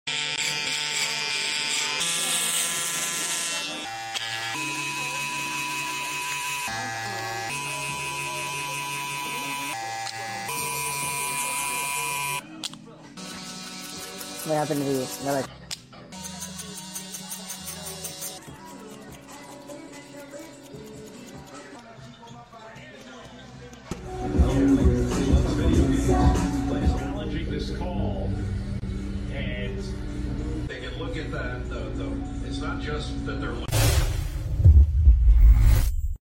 ASMR Sounds